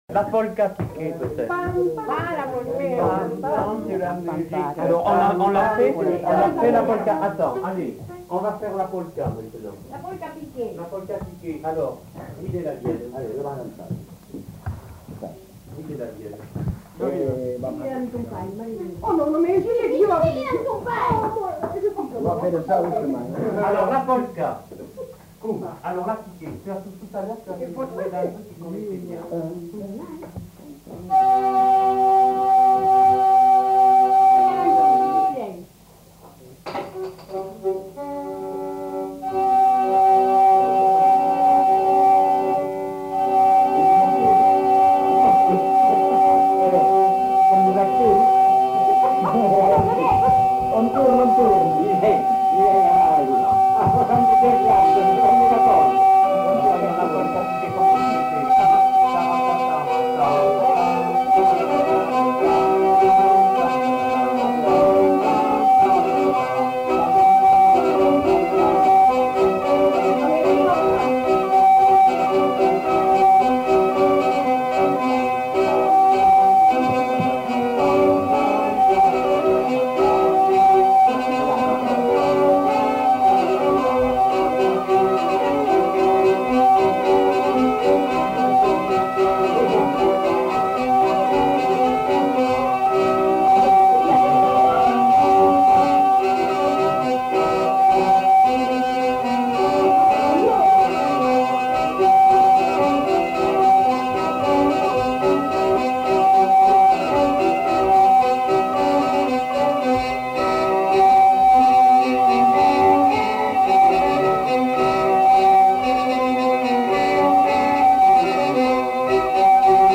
Aire culturelle : Grandes-Landes
Lieu : Luxey
Genre : chant
Type de voix : voix mixtes
Production du son : chanté
Danse : polka piquée